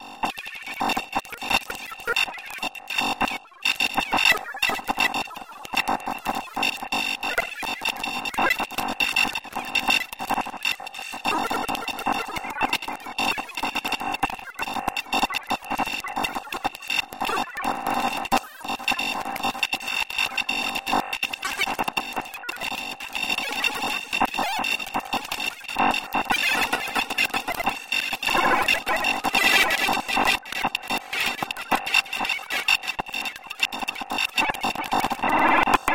Звук цифрового кодирования